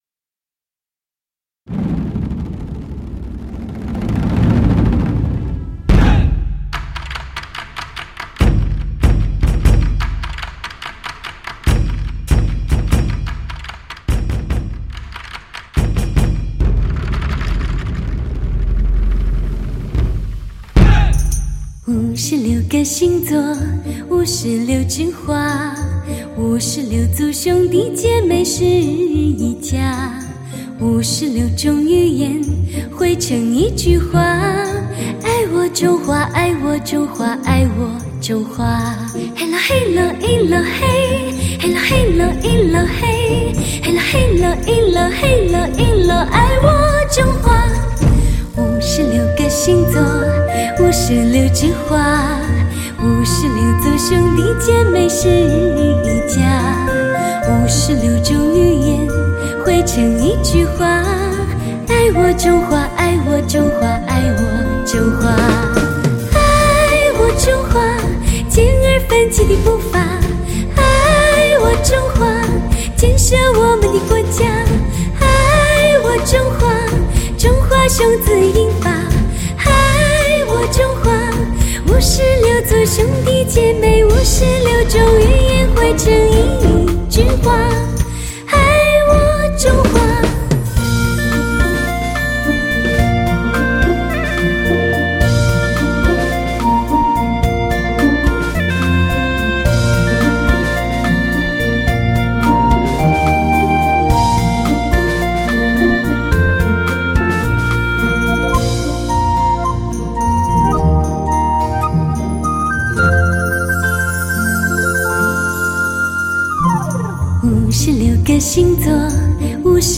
人声轻巧甜美、秀丽、行腔婉转流畅，咬字清晰，表现细腻动人，势必轰动发烧界。
情感真挚的人声，一如三十多年前的青春那般热烈、纯真、奔放、毫不褪色也不凋谢。